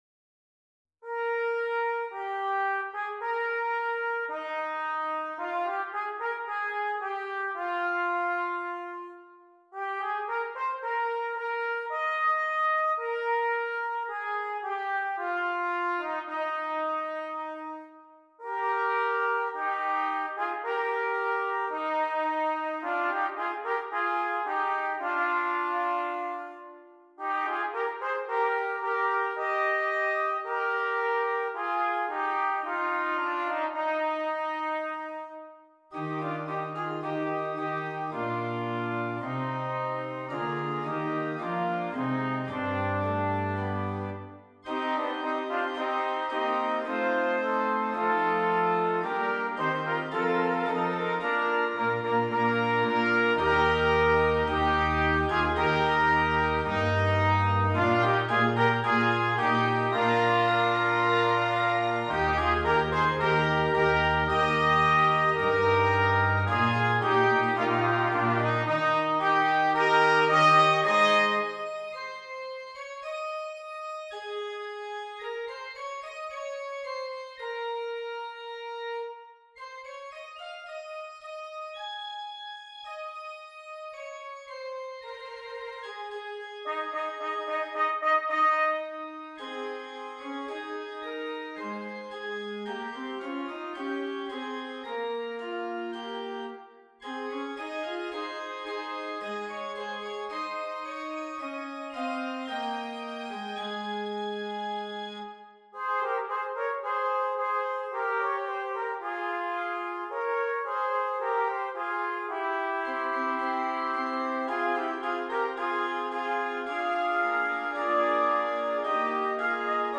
2 Trumpets and Keyboard